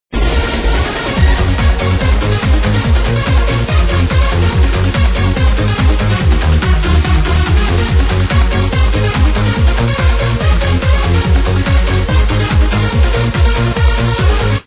Sounds very cheesy, a wild guess would be Yahel?